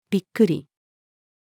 びっくり-female.mp3